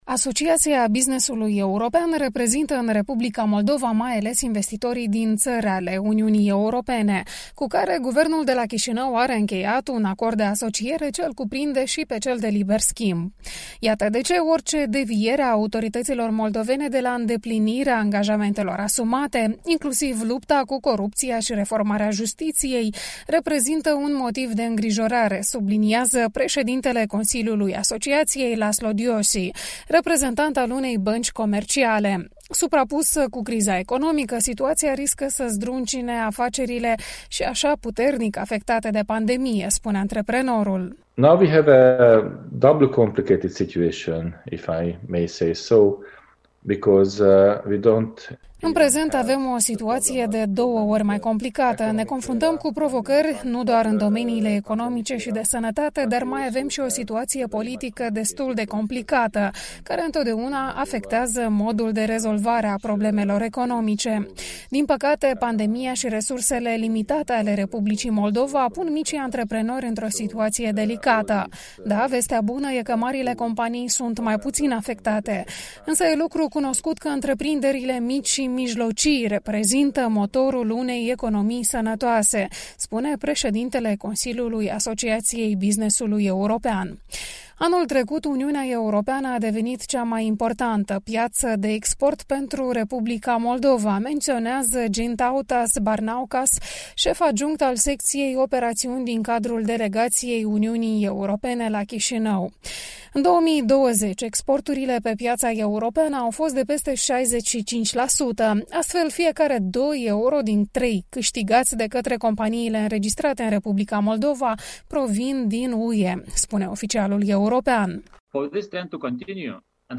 În cadrul unei dezbateri organizată de Asociația Businessului European, antreprenori din străinătate ce dezvoltă afaceri în R. Moldova au îndemnat autoritățile, în pofida neînțelegerilor pe teme politice, să continue reformele agreate cu Uniunea Europeană și să asigure finanțarea externă.